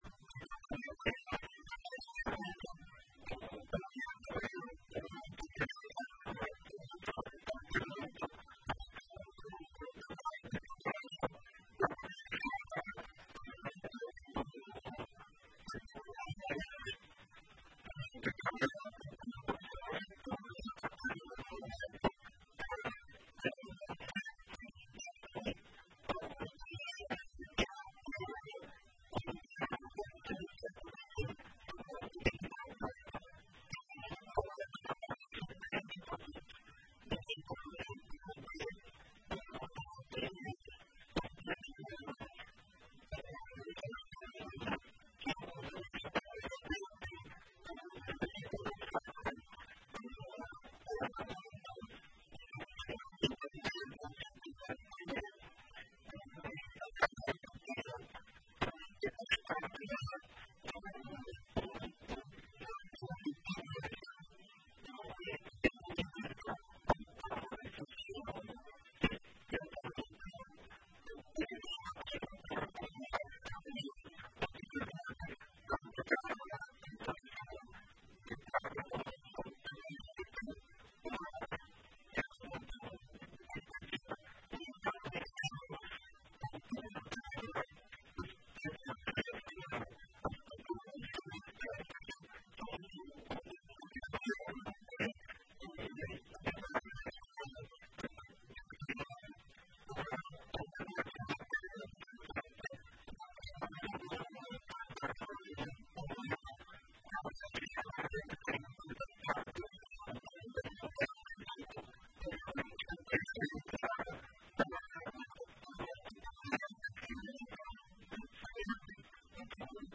Em entrevista à Rádio Progresso nesta manhã (24), o prefeito de Ajuricaba, Paulo Dolovitsch, detalhou sobre os prejuízos econômicos registrados no município em razão da seca.